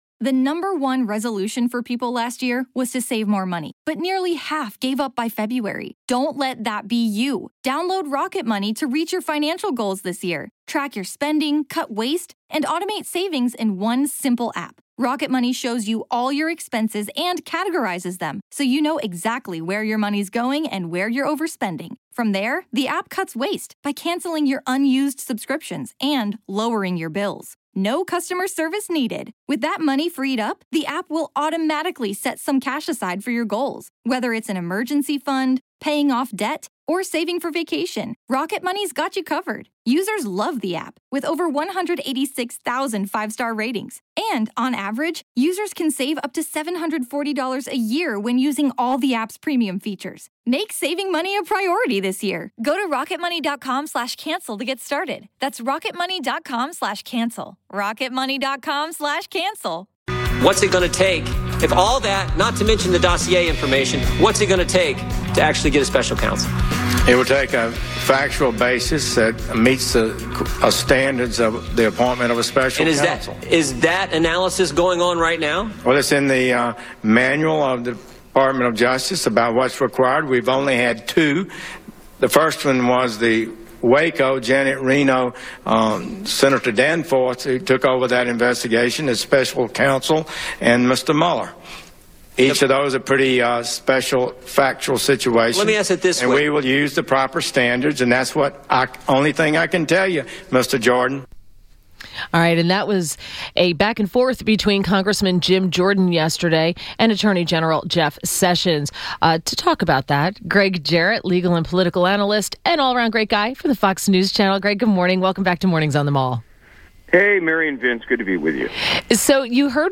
INTERVIEW – GREGG JARRETT – legal and political analyst for Fox New Channel – discussed AG Sessions on the hill yesterday.